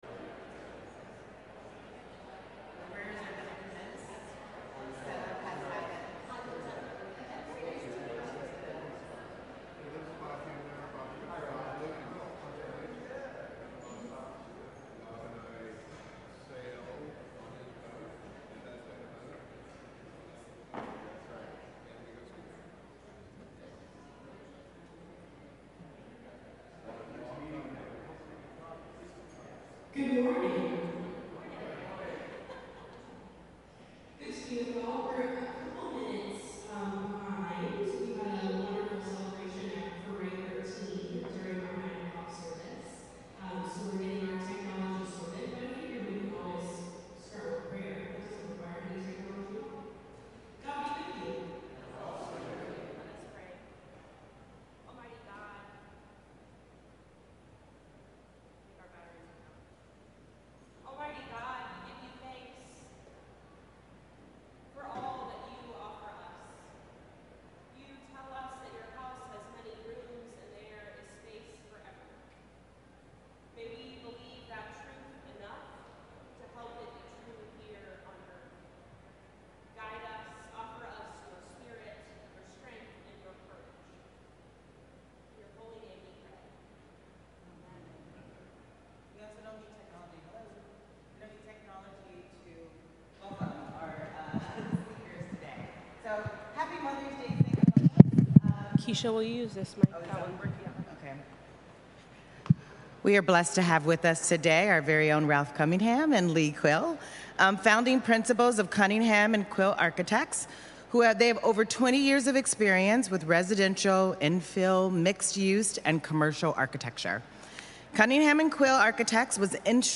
Sunday Forum from St. Columba's in Washington, D.C.